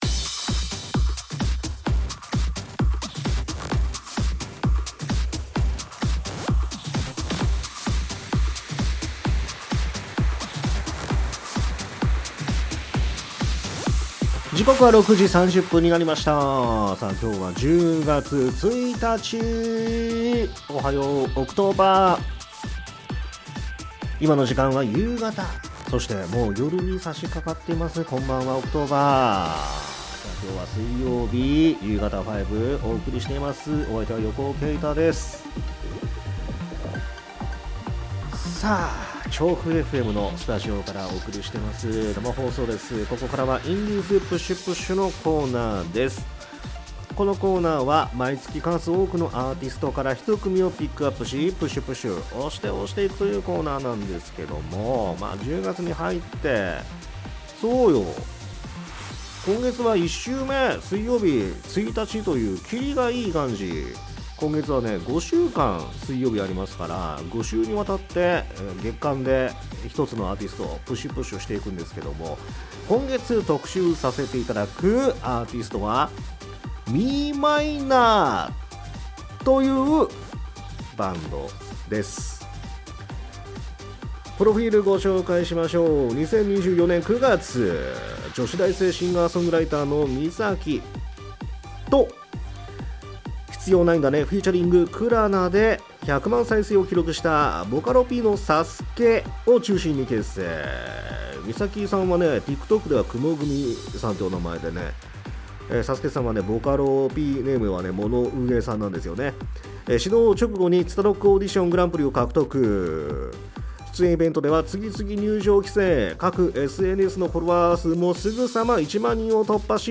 ※アーカイブでは楽曲カットしています